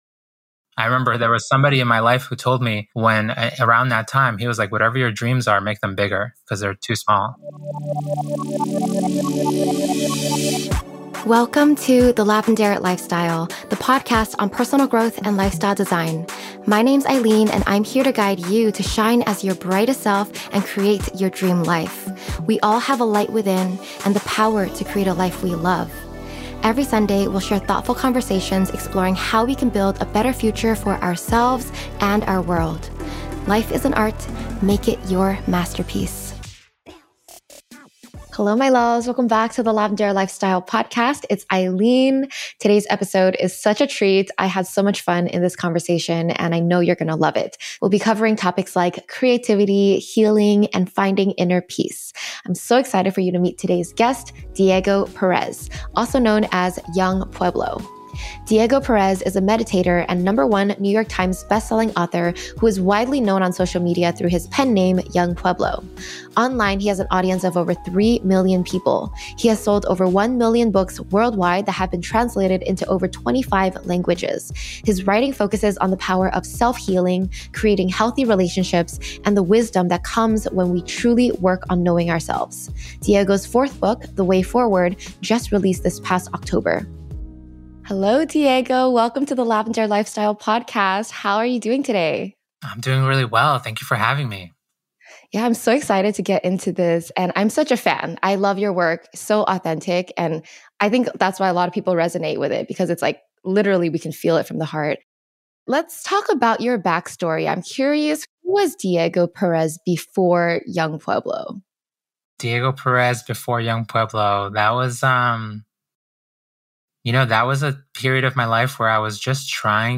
📹The video version of this interview is available on YouTube: Episode 254